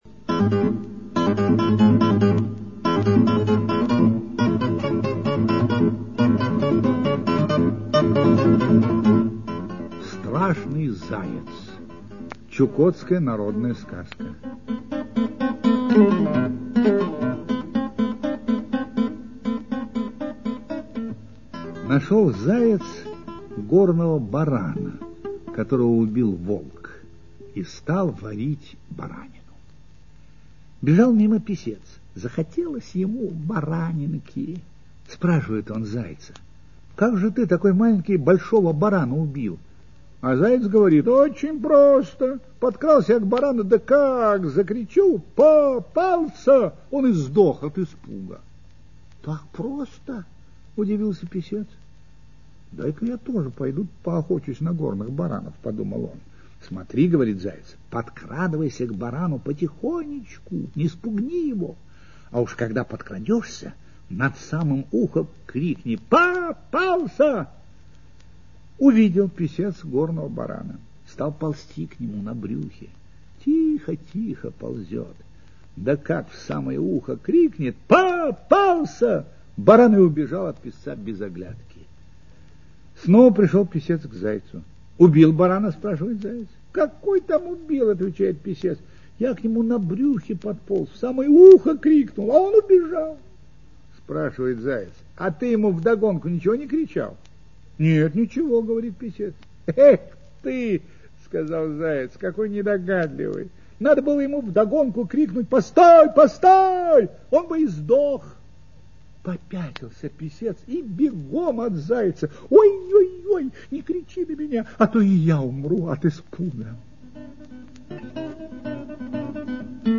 Чукотская аудиосказка
Текст читает Ростислав Плятт.